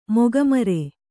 ♪ moga mare